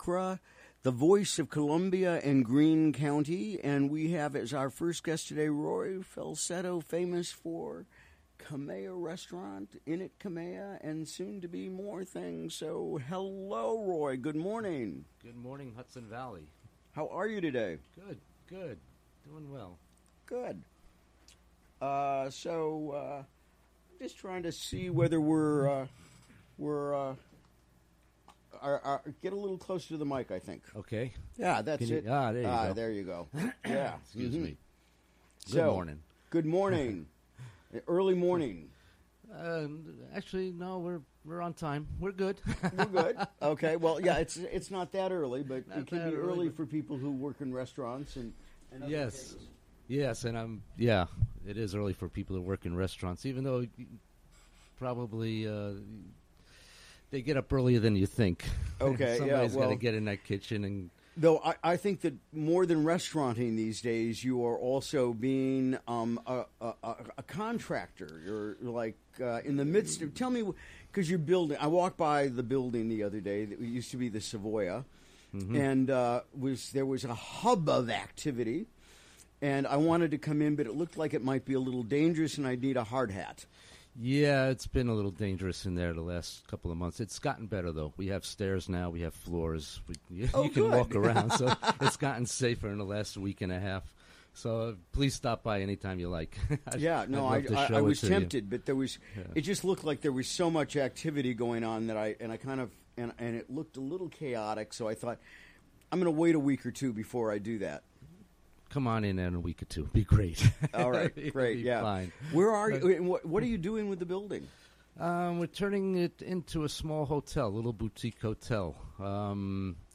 Recorded during the WGXC Morning Show on Wednesday, July 12.